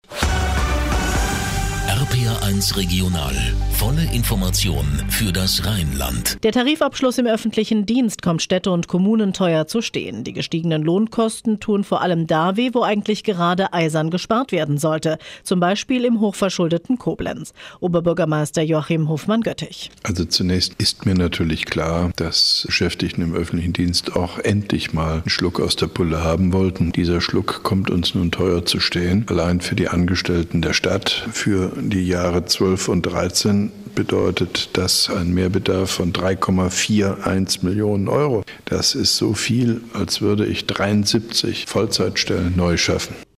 Ausschnitt: RPR1 Regional, Informationen für das Rheinland, Studio Koblenz, 8.30 Uhr ff.,  05.04.2012
Mit einem Kurzinterview von OB Hofmann-Göttig